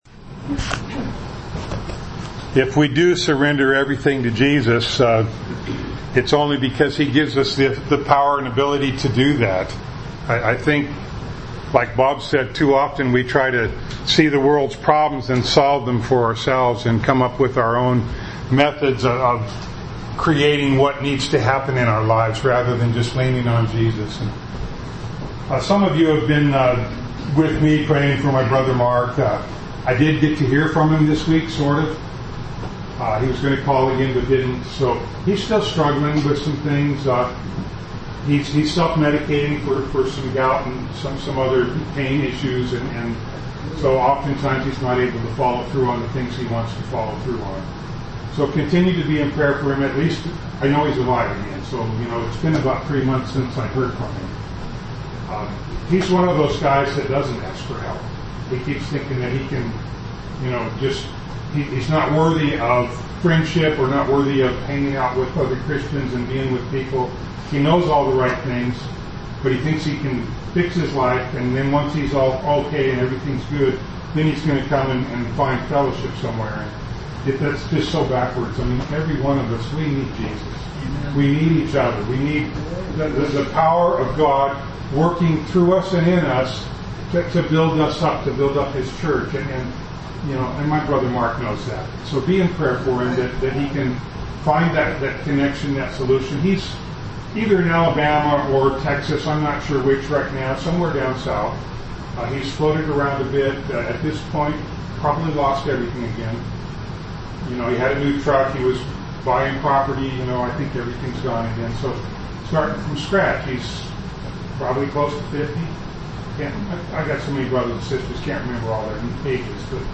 Ephesians 4:11-13 Service Type: Sunday Morning Bible Text